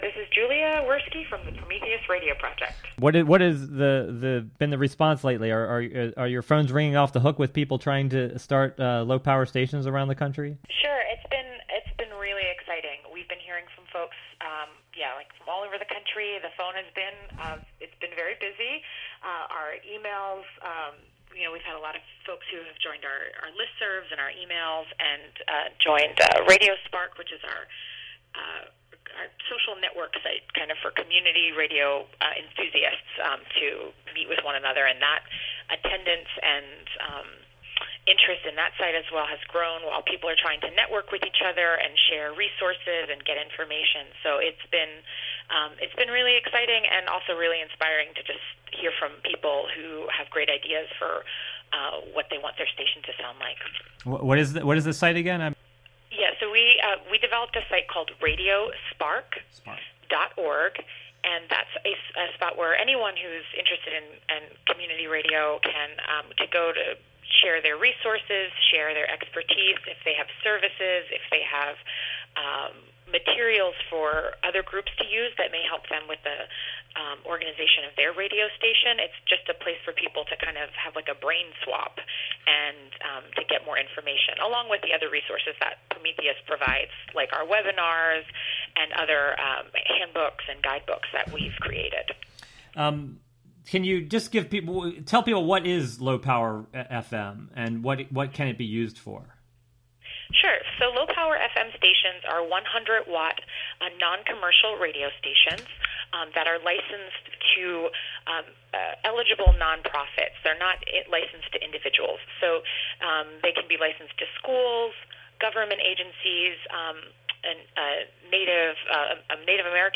Interviews, features, and information about low-po